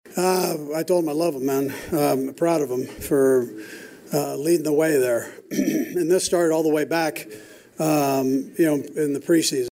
Chiefs head coach Andy Reid says he’s proud of quarterback Patrick Mahomes.